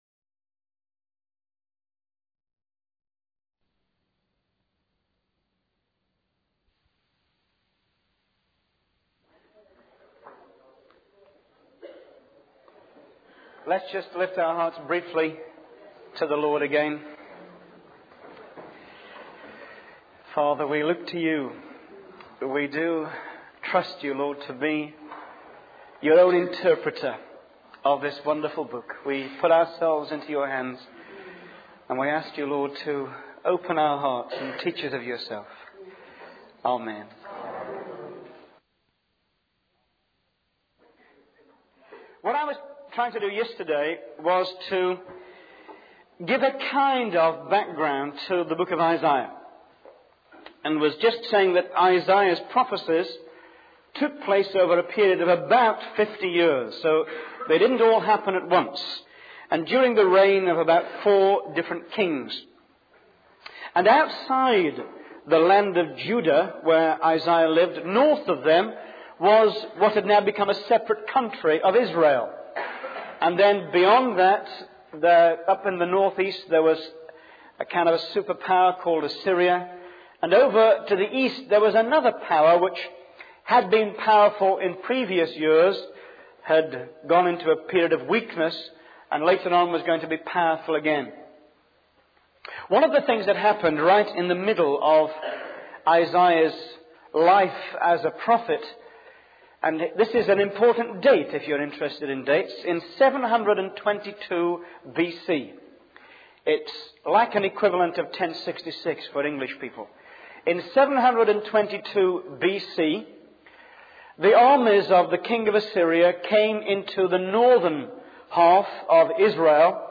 In this sermon, the preacher emphasizes the importance of preparing the way for the Lord.